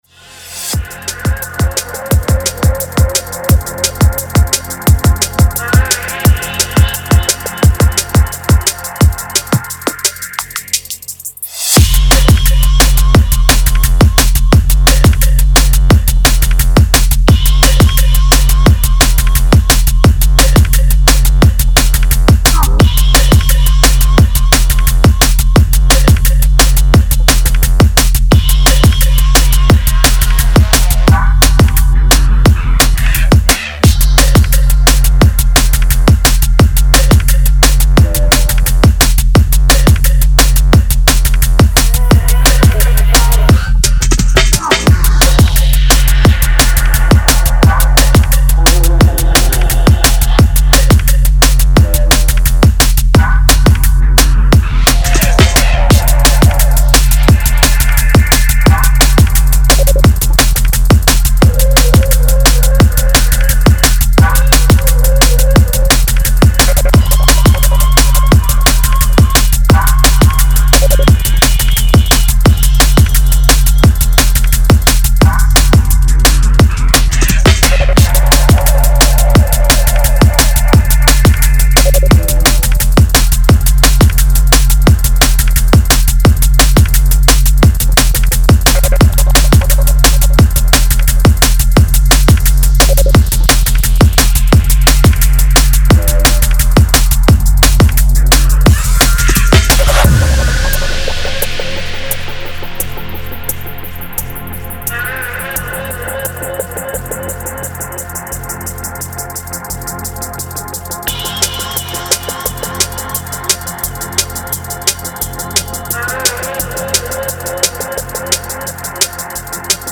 dark and brooding cuts